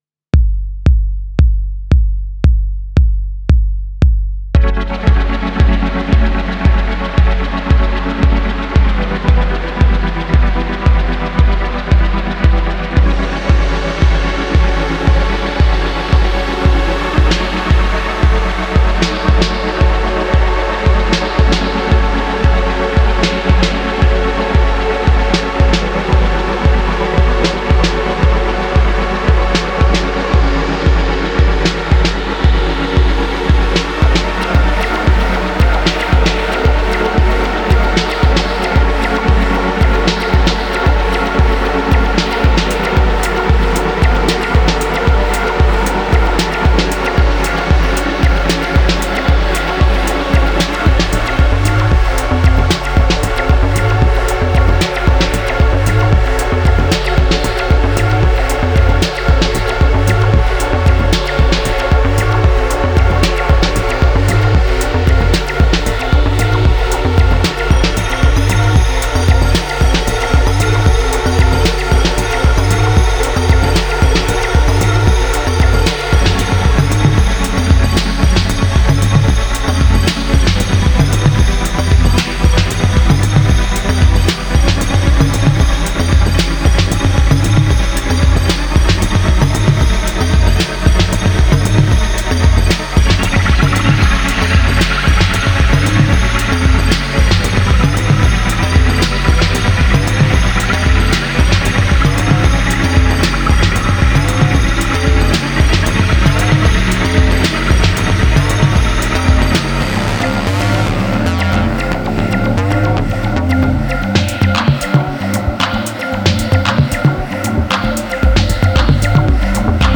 Luminous glo-fi post-house and dense electro maximalism.